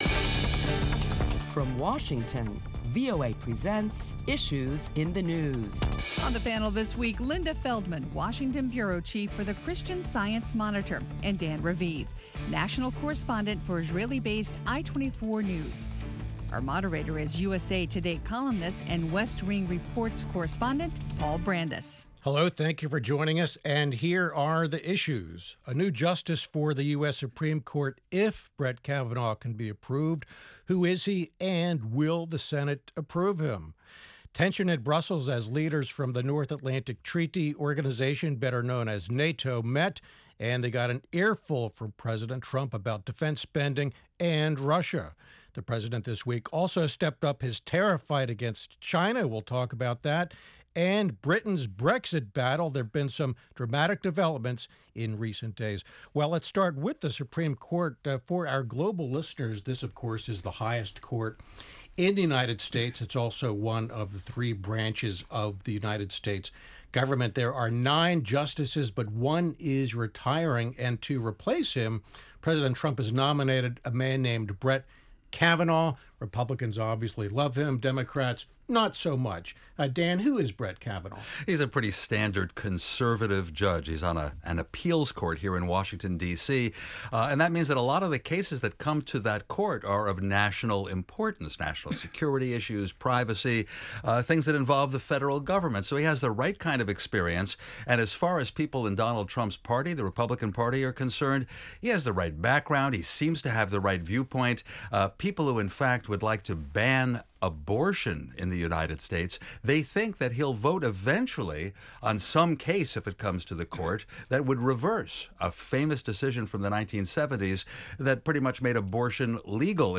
Join Washington correspondents for a lively discussion of the week's top stories including President Trump’s meetings with NATO and the UK's "Brexit" from the EU.